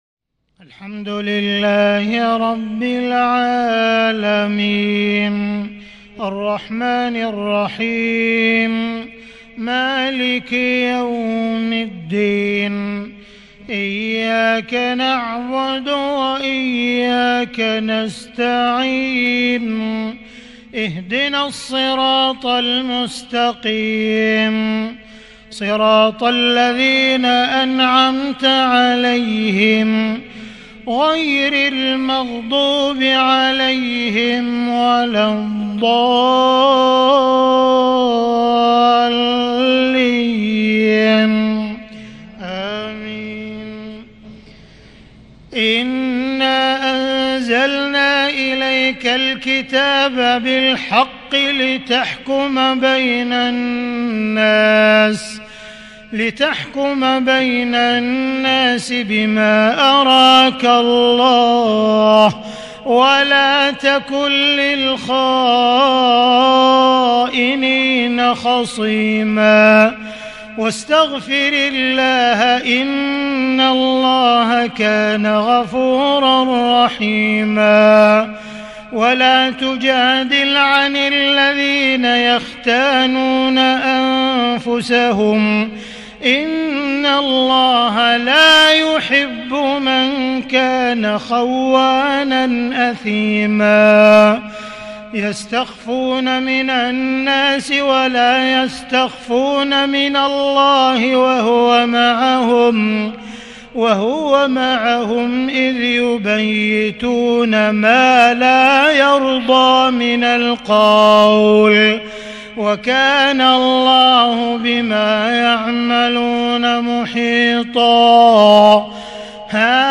صلاة العشاء ٢٠ محرم ١٤٤٢هـ من سورة النساء | Isha Prayer from Surah An-Nisa 8-9-2020 > 1442 🕋 > الفروض - تلاوات الحرمين